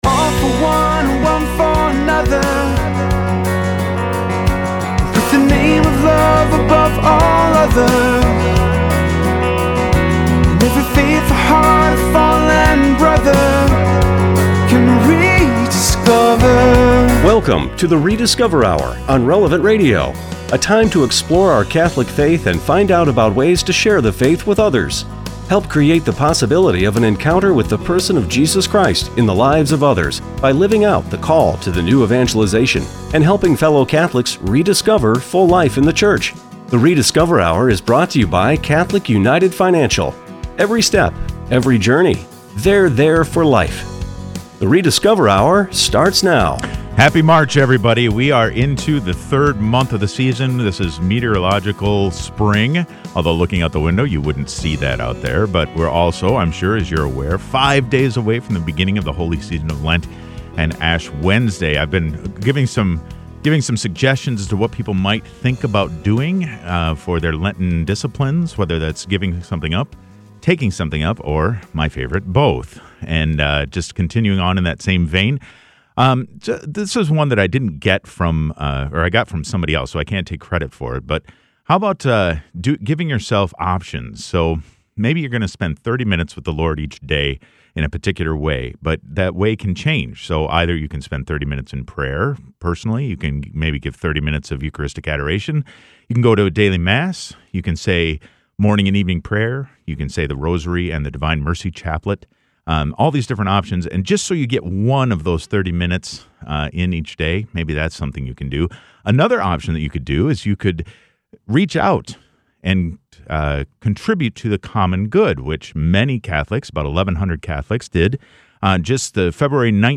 On the Rediscover: Hour, listen to interviews taken at Catholics at the Capitol this past February.